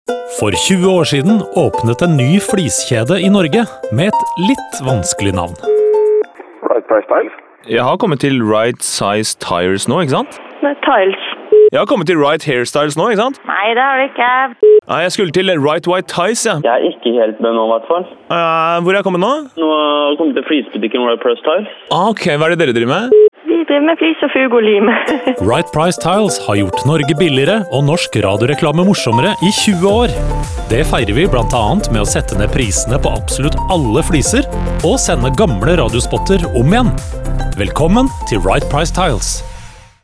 Telefonsamtaler er et grep som er brukt en del i radioreklame, som også gjør jobben enda mer krevende for neste annonsør ut.